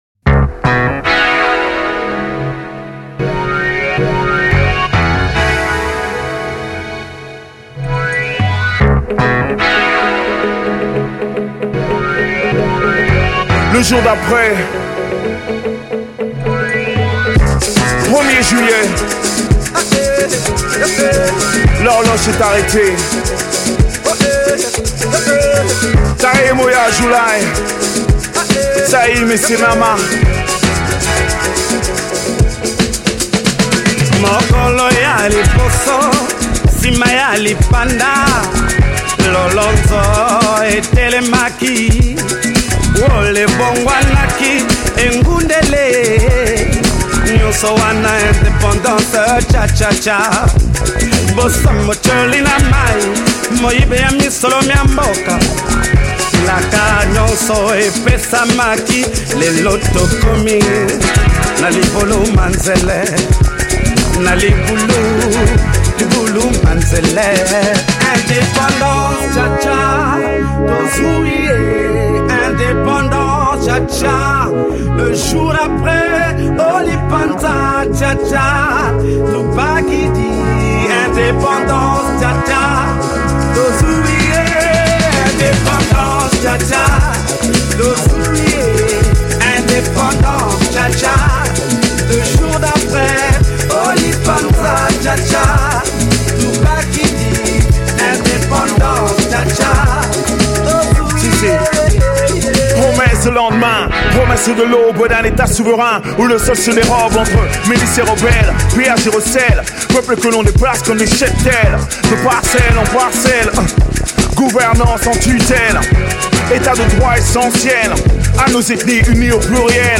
Congolese singer/MC